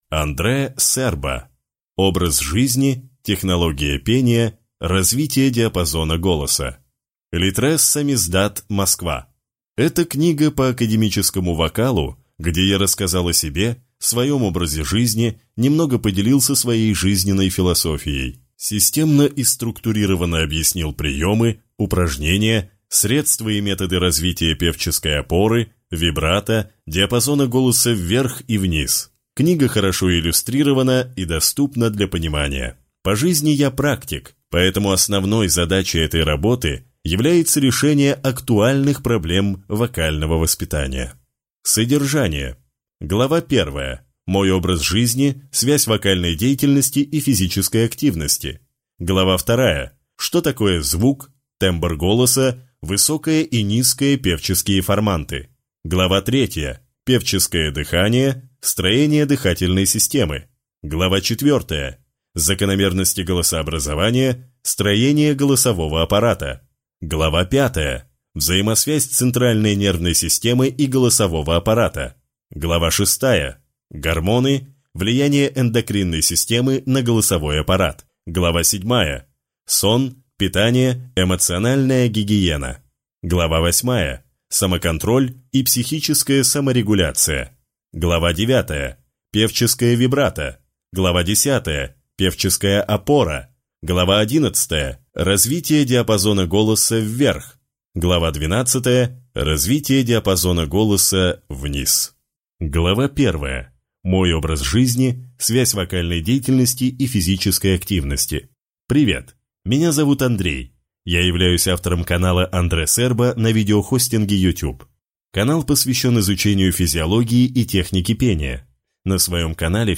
Аудиокнига Образ жизни. Технология пения. Развитие диапазона голоса | Библиотека аудиокниг